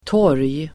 Uttal: [tår:j]